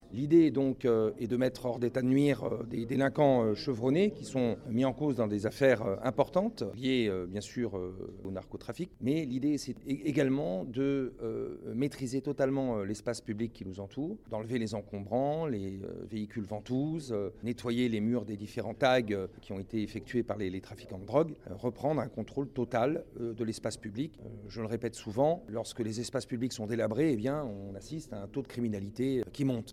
Pour Hugues Mouthou, le préfet des Alpes-Maritimes, explique à notre micro que " l 'idée est de mettre hors d'état de nuire des délinquants chevronnés qui sont mis en cause dans des affaires importantes.